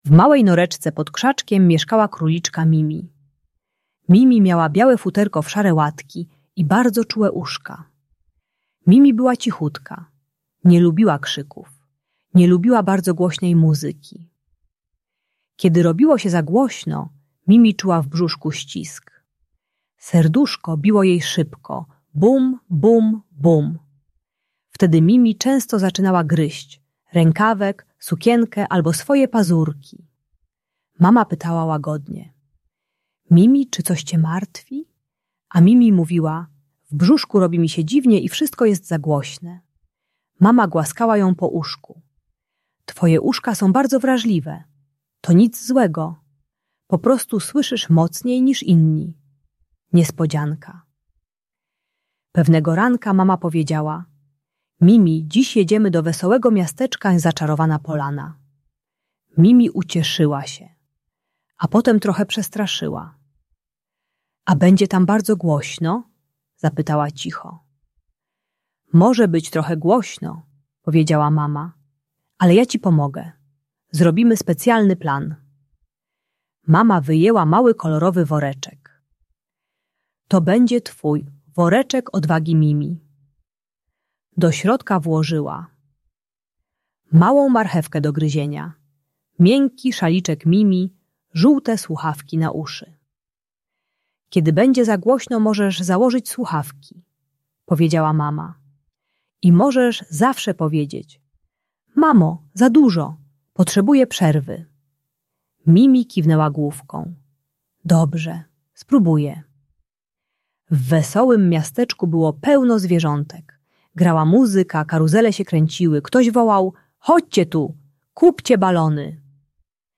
Historia Mimi - Niepokojące zachowania | Audiobajka